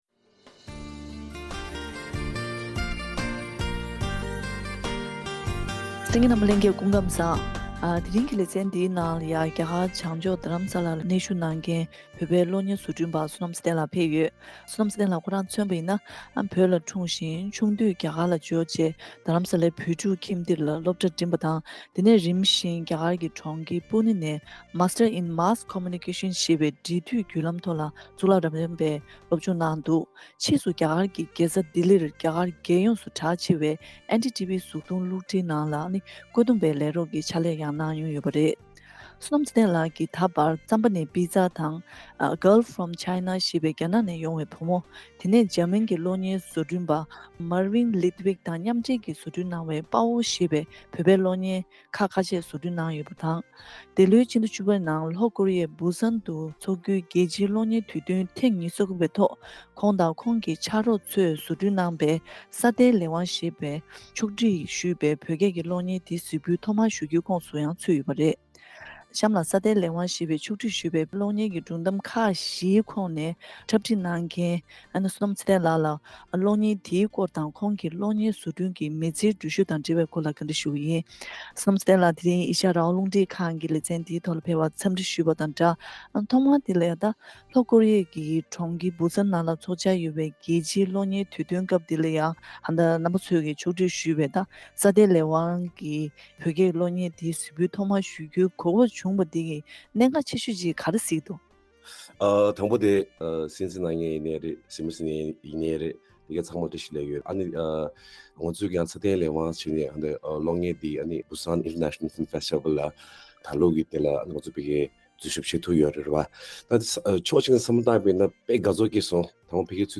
བཀའ་དྲི་ཞུས་པ་ཞིག་གསན་གནང་གི་རེད།